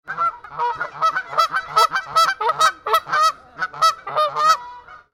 جلوه های صوتی
دانلود صدای پرنده 1 از ساعد نیوز با لینک مستقیم و کیفیت بالا
برچسب: دانلود آهنگ های افکت صوتی انسان و موجودات زنده دانلود آلبوم صدای پرندگان از افکت صوتی انسان و موجودات زنده